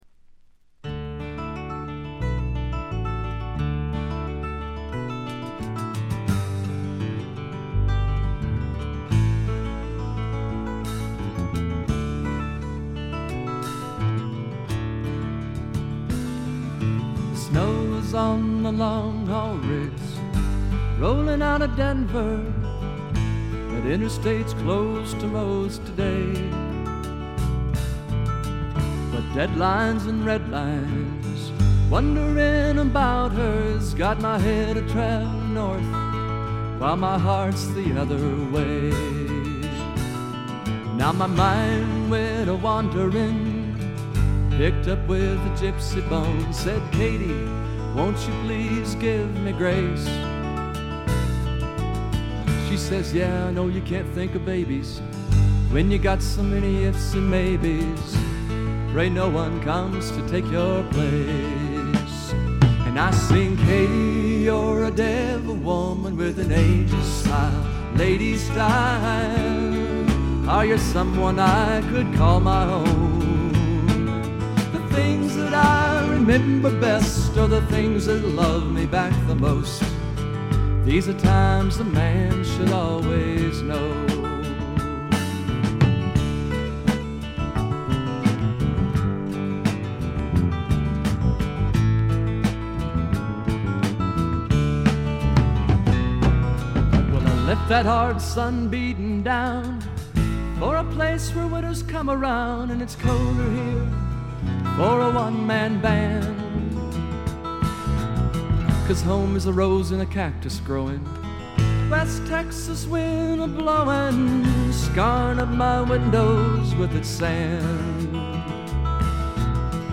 ところどころでバックグラウンドノイズ、チリプチ。
試聴曲は現品からの取り込み音源です。
Lead Vocals, Acoustic Guitar